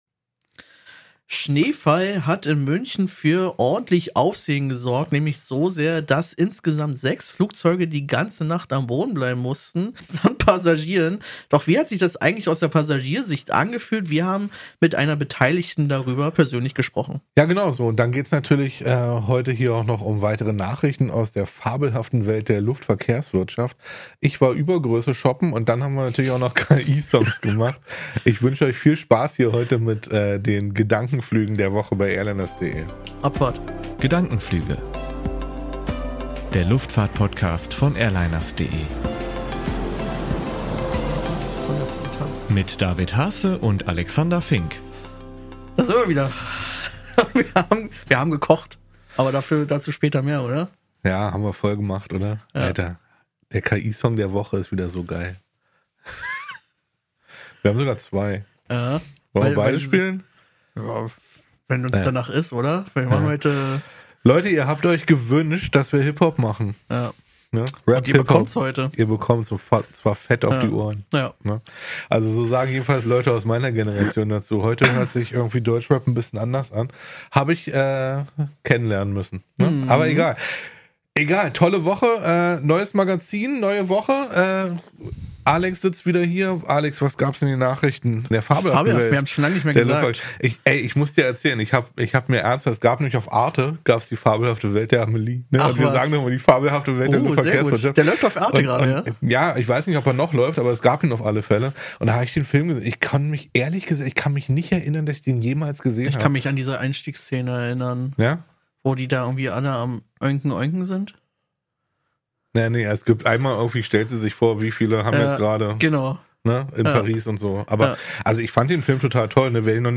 Außerdem: Das Condor Mittelsitz-Dilemma – und zwei KI-Songs, die das alles amüsant verarbeiten.